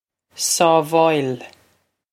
Sábháil Sawv-oy-il
This is an approximate phonetic pronunciation of the phrase.